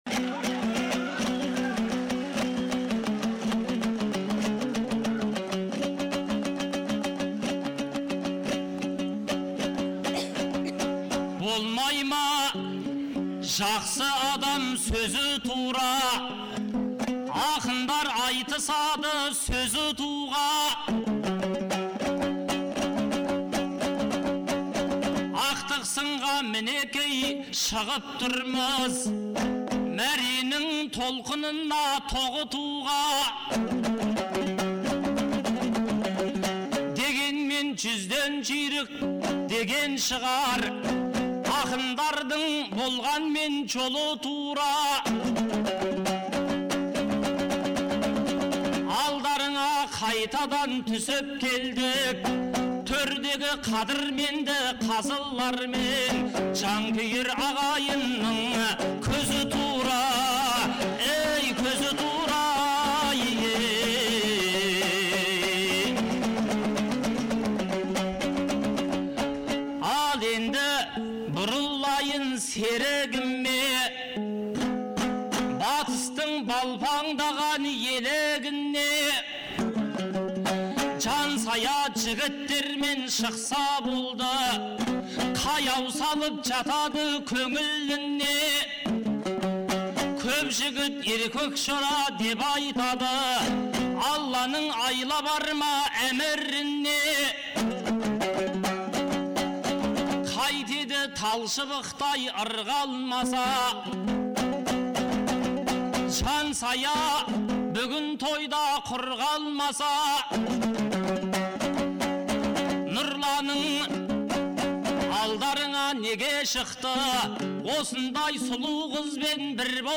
Наурыздың 15-16-сы күндері Шымкент қаласында екі күнге созылған республикалық «Наурыз» айтысы өтті. 2004 жылдан бері тұрақты өтіп келе жатқан айтыс биыл Төле бидің 350 және Абылай ханның 300 жылдықтарына арналды.